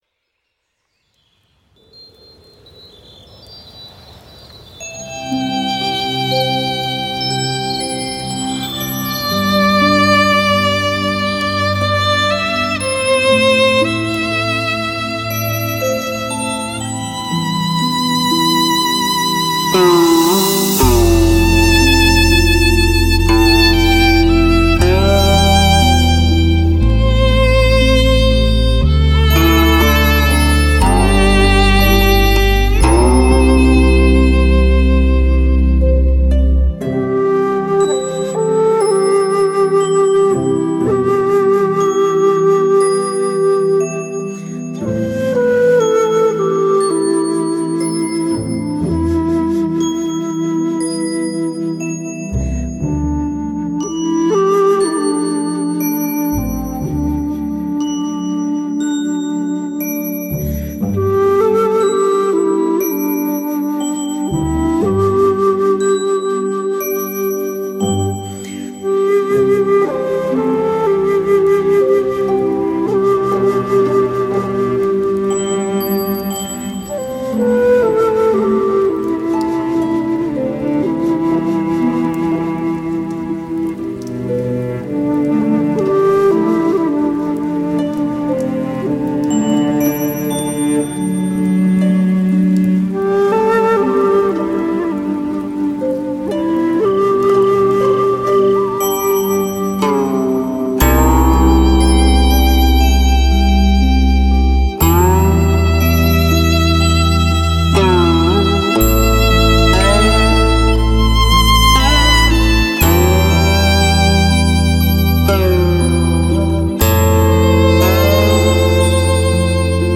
古琴 洞箫 琵琶 优美清雅 如听仙乐
禅曲 禅画 禅诗 淡远虚静 如入山林
这是一张将禅画诗曲集于一体的佛曲专辑，禅意十足。
古琴、箫、大提琴